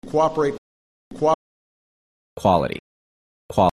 The two syllables of kəwop or kowop can weaken towards one-syllable kwop. This means that the beginning of coop- can sound rather like the beginning of quality: